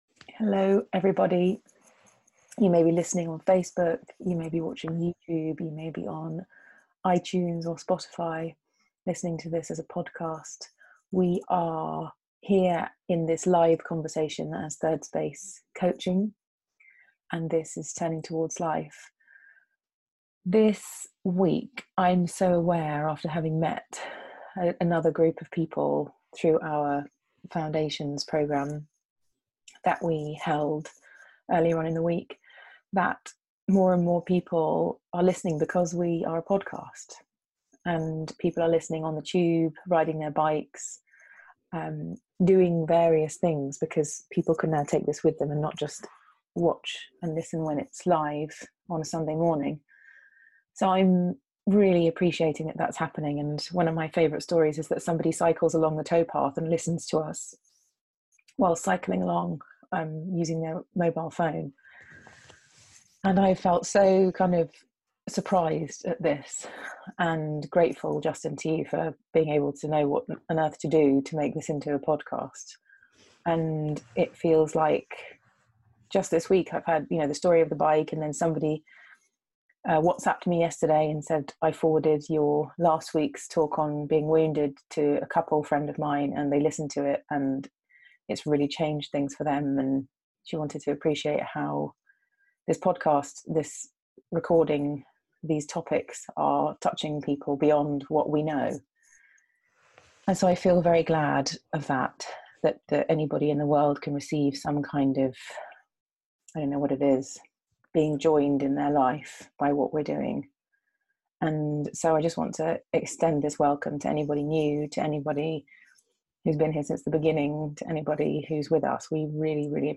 weekly live 30 minute conversation